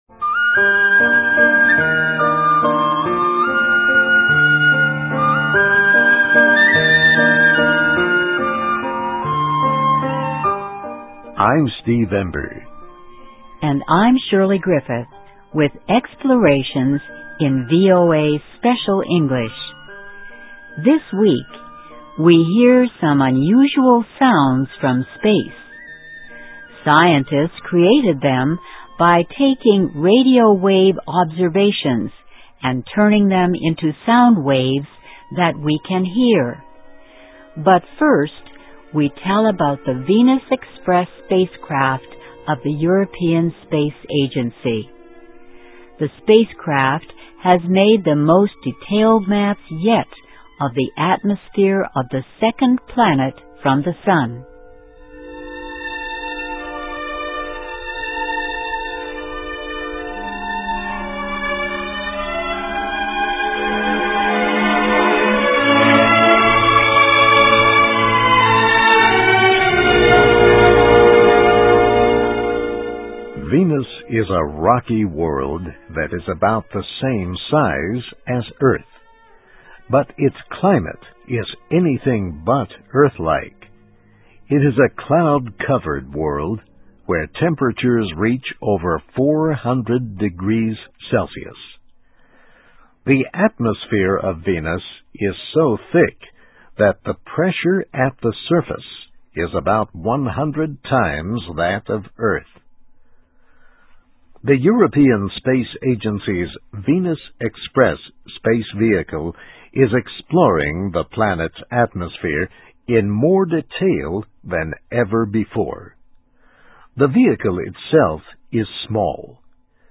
This week, we hear some unusual sounds from space. Scientists created them by taking radio wave observations and turning them into sound waves that we can hear.